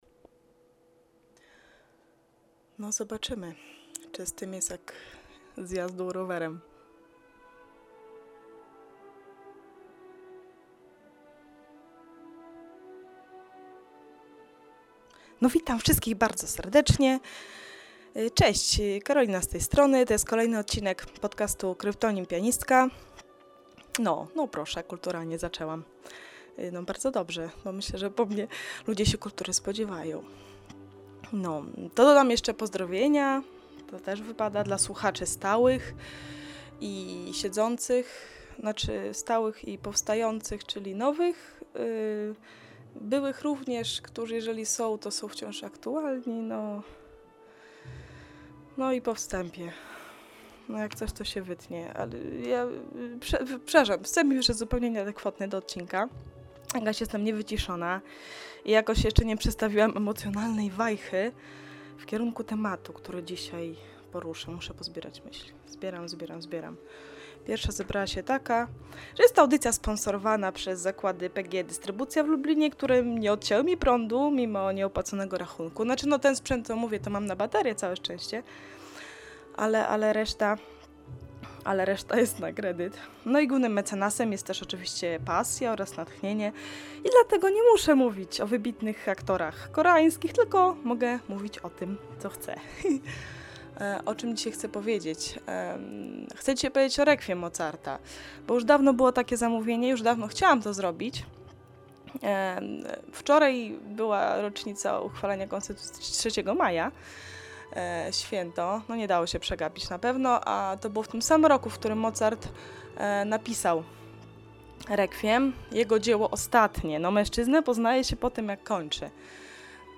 Momentami trochę Cie wciąż słabo słychać, a szybko mówisz.